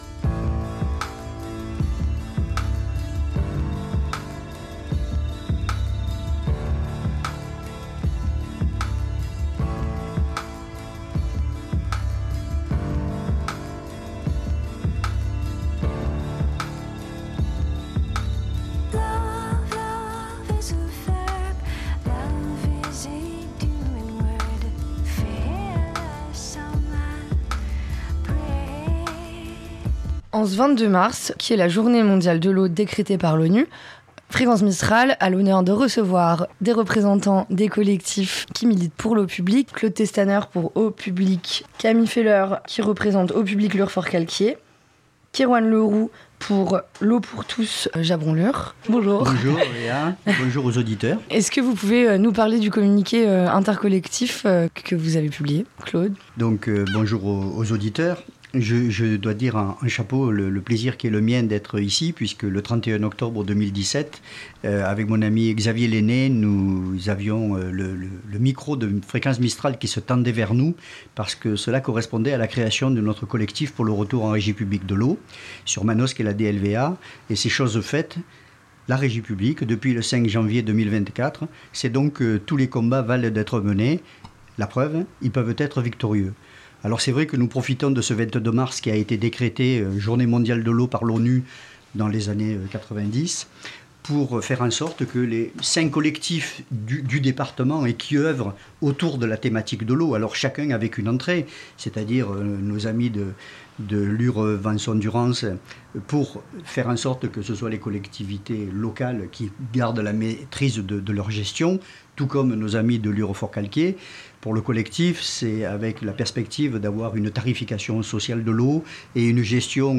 trois représentants des collectifs reviennent sur les enjeux spécifiques au 04 ainsi qu'au contexte de la loi NOTRE.